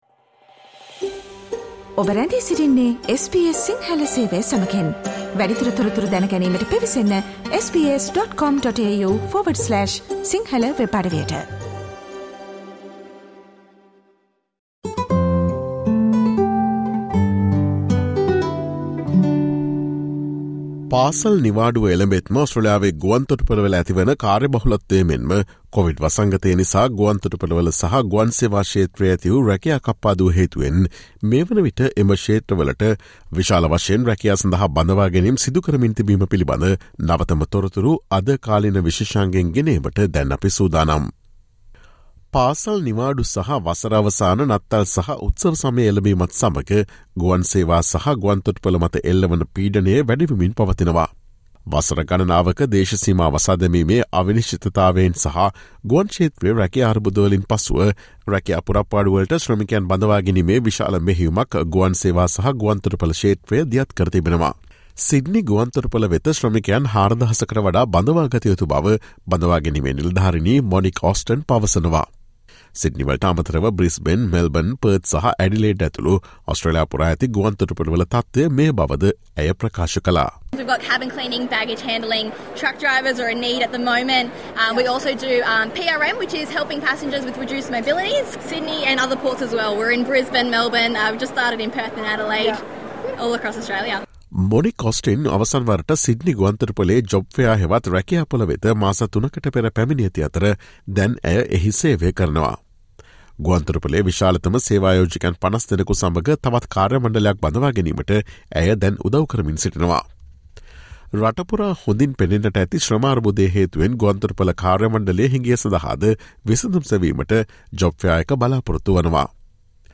After years of border uncertainty and job struggles across the Air travel industry, it's now launching a recruitment blitz. Listen to the SBS Sinhala Radio's current affairs feature broadcast on Thursday 22 September.